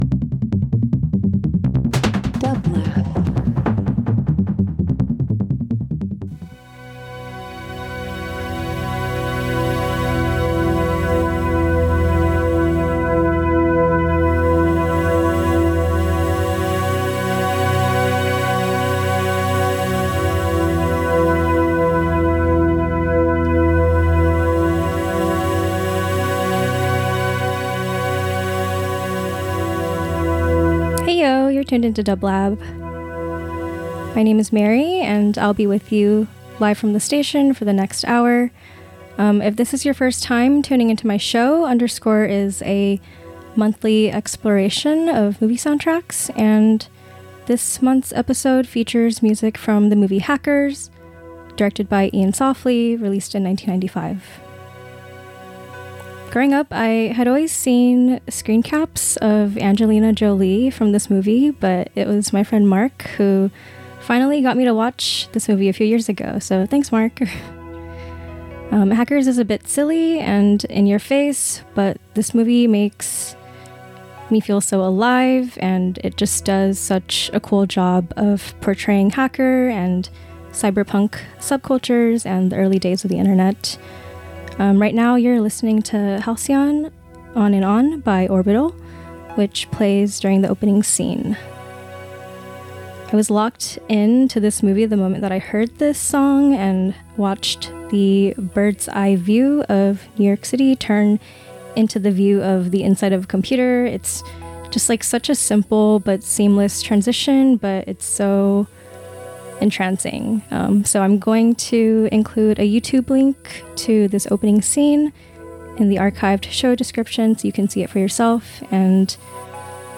Ambient Dubstep Hip Hop Soul Soundtracks